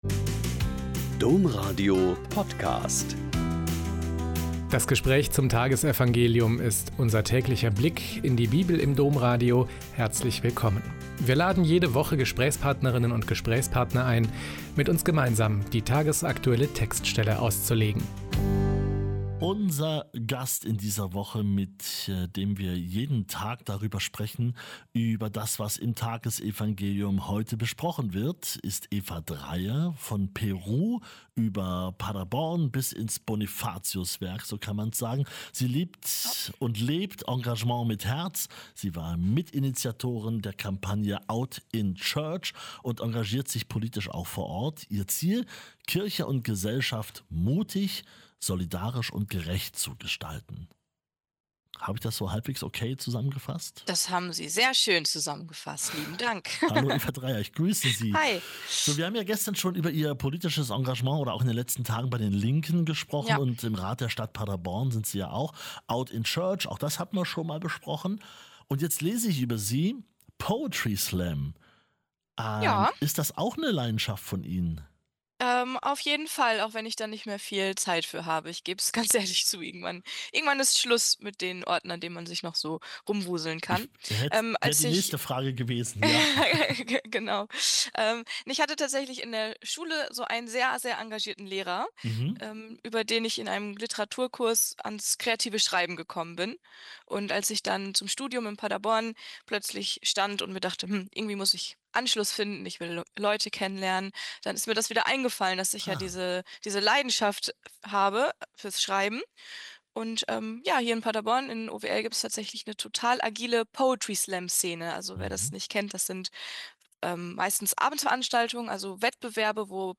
Lk 17,20-25 - Gespräch